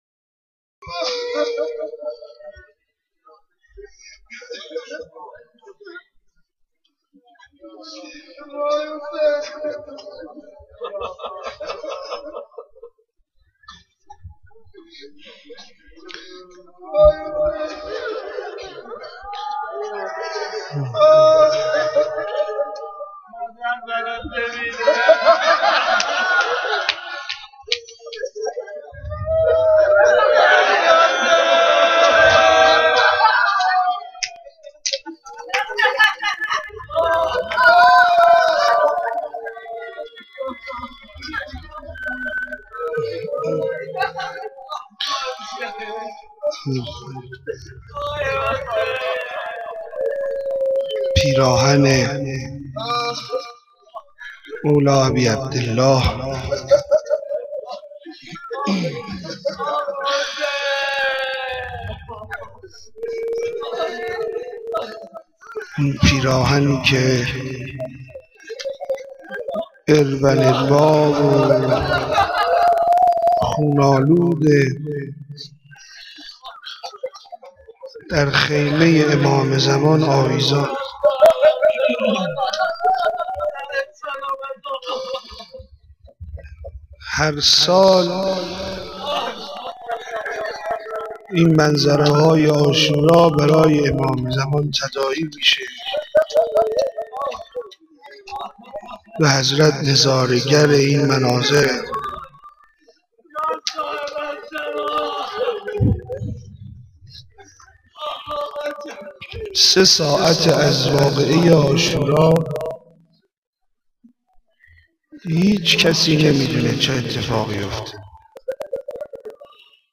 روضه خوانی10.wma
روضه-خوانی10.wma